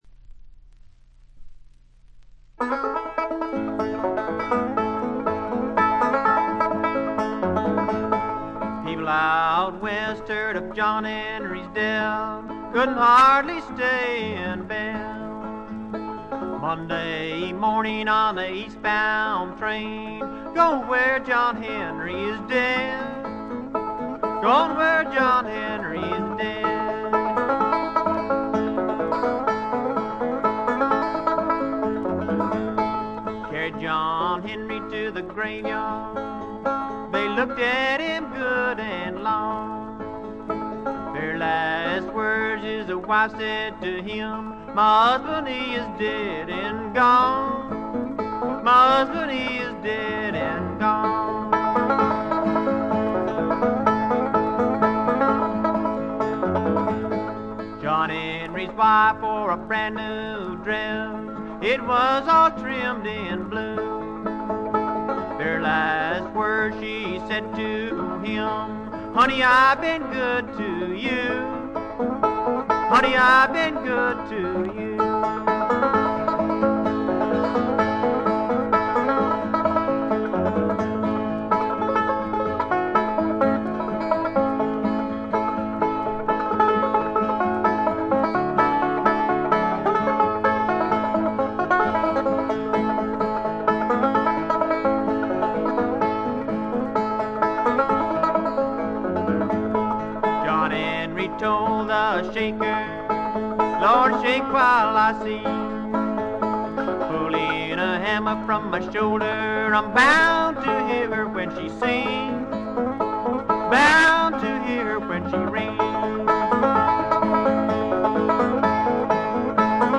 原初のブルーグラスの哀愁味あふれる歌が素晴らしいです！
試聴曲は現品からの取り込み音源です。